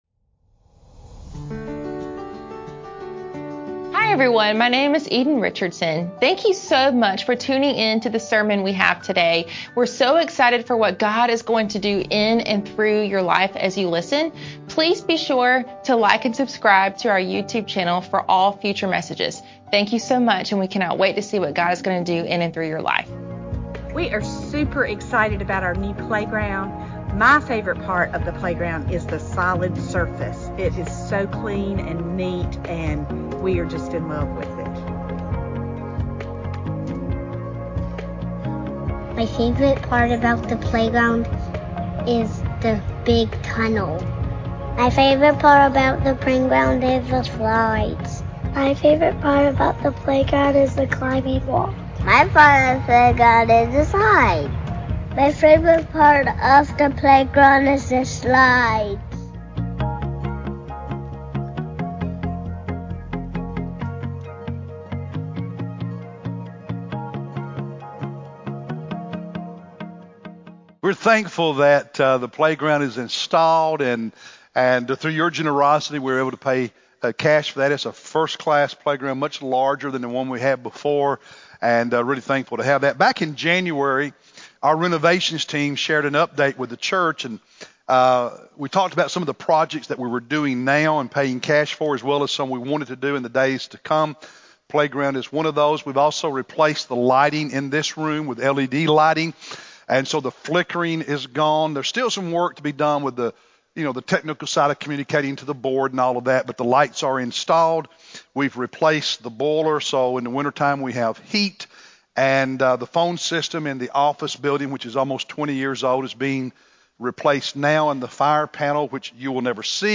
March-15-Sermon-CD.mp3